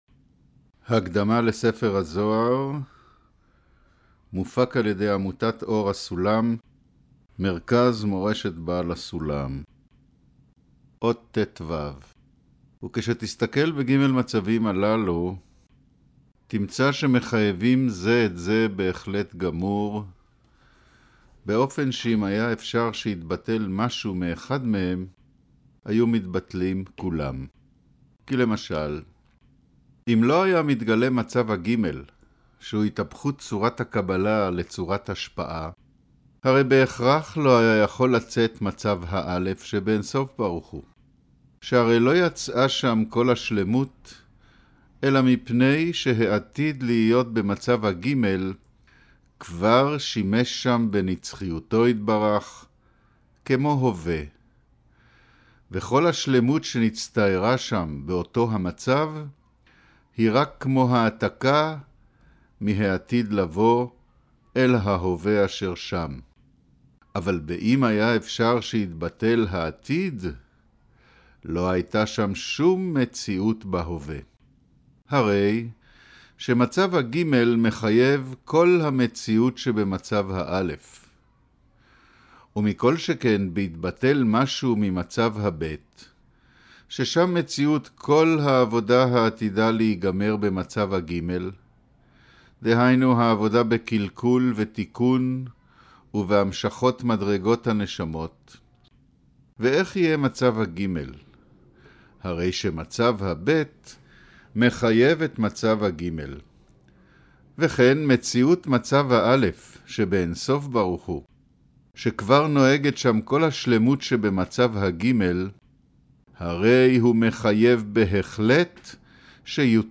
קריינות